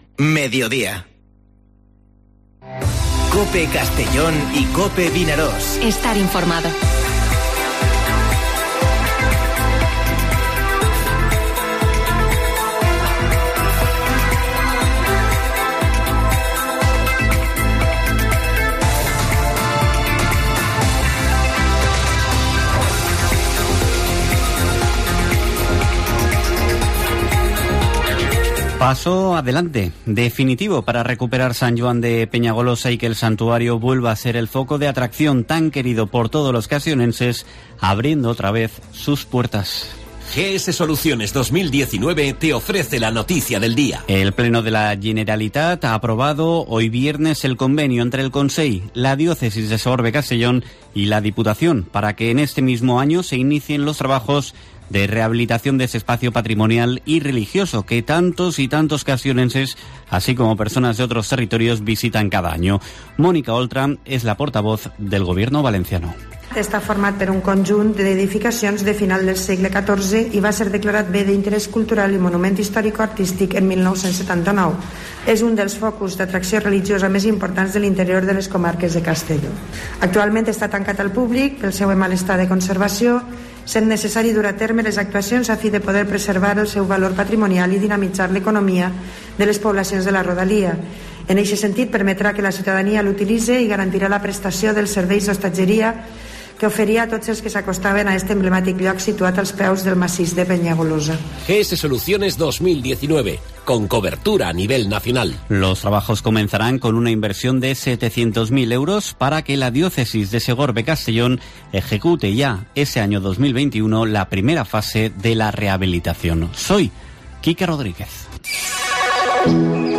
Informativo Mediodía COPE en la provincia de Castellón (14/05/2021)